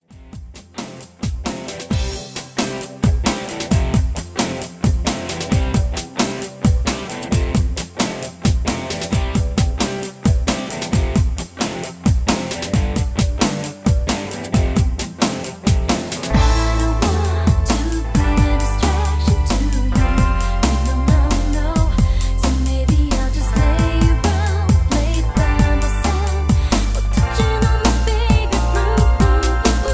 Karaoke MP3 Backing Tracks
Just Plain & Simply "GREAT MUSIC" (No Lyrics).